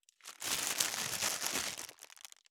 610コンビニ袋,ゴミ袋,スーパーの袋,袋,買い出しの音,ゴミ出しの音,袋を運ぶ音,
効果音